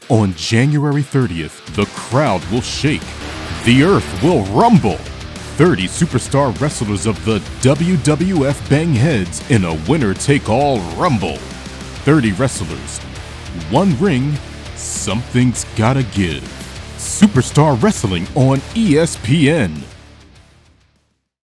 TV Promo Samples
High Energy
TV-Promo-Demo_Superstar-Wrestling.mp3